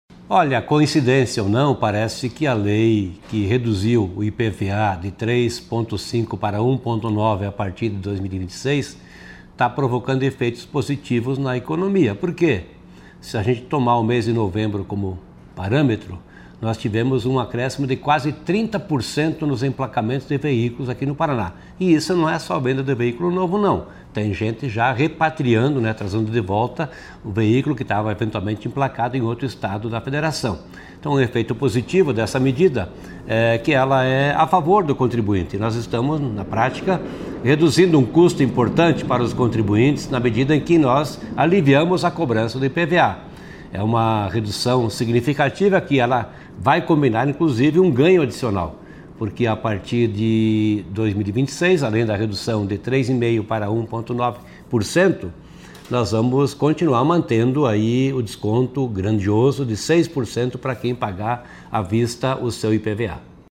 Sonora do secretário da Fazenda, Norberto Ortigara, sobre o aumento no número de emplacamentos com a redução do IPVA